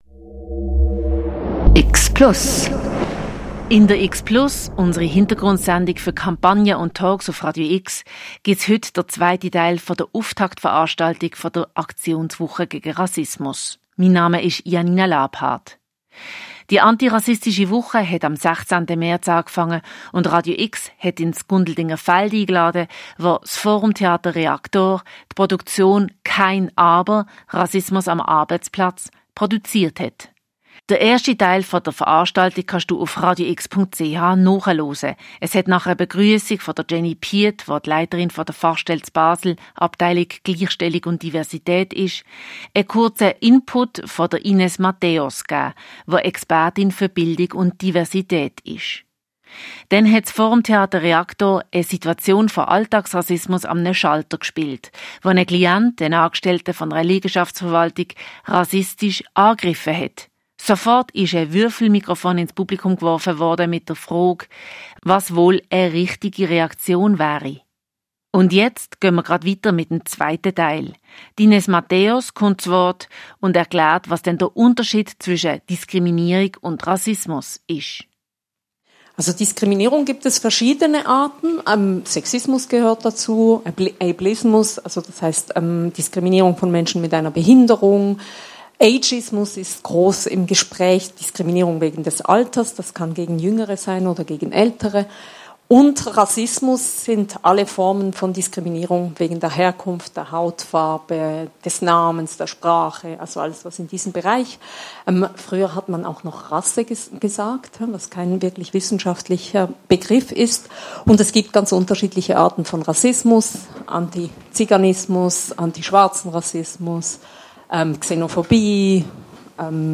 Am Montagabend, 16. März 2026 fand eine interaktive Veranstaltung im Gundeldingerfeld in Basel statt. Reactor stellte verschiedene Szenen auf der Bühne dar.